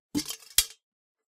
Kenney's Sound Pack/Foley Sounds/Helmet • Directory Lister
pickup3.ogg